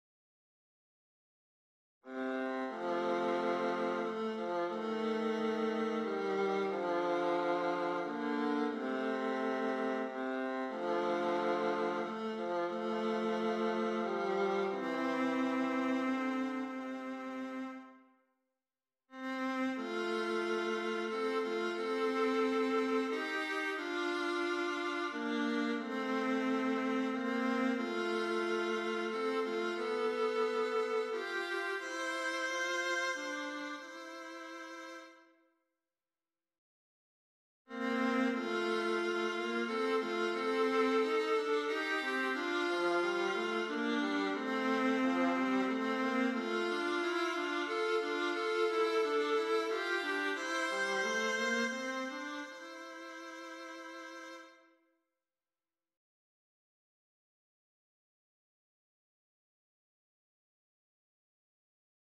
15 Arrangements with 3 Verses, 46 Total Pages